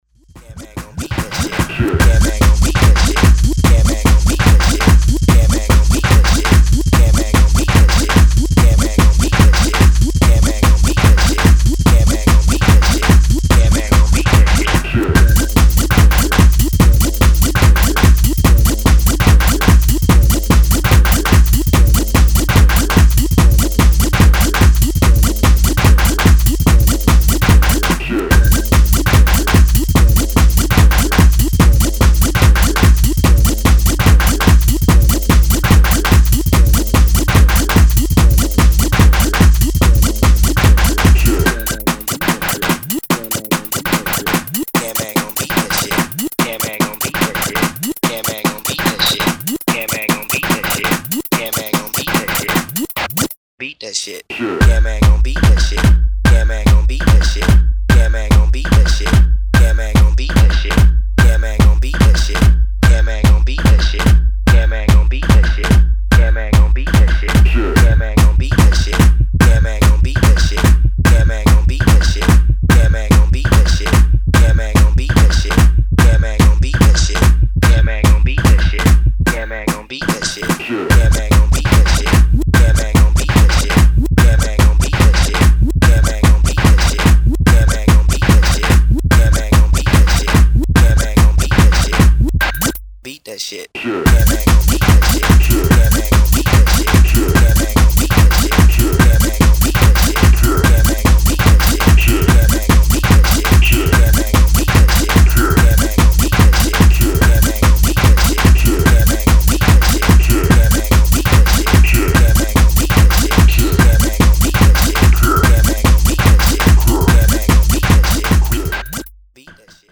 Style: Hard Techno / Chicago